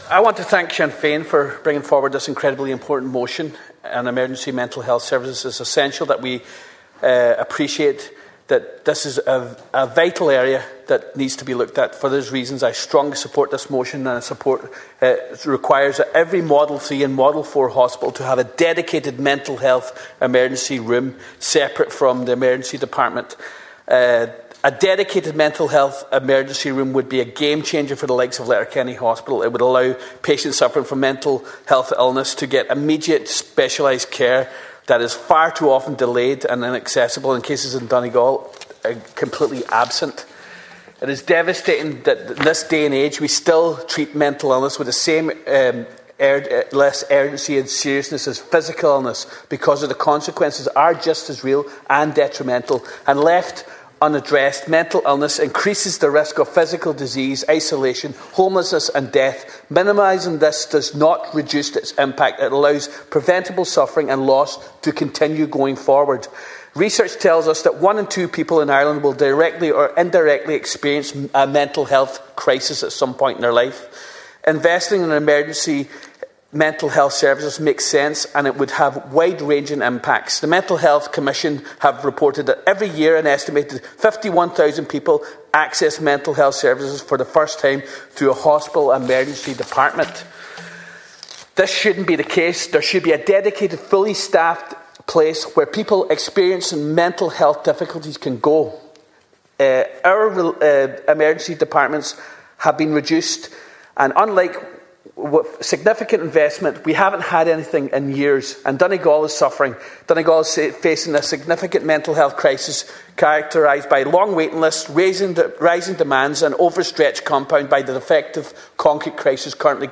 Deputy Ward was speaking during a Sinn Fein motion requiring all Model 3 and Model 4 hospitals to provide a dedicated mental health emergency room, separate from emergency departments.
You can listen to Deputy Wards full contribution here –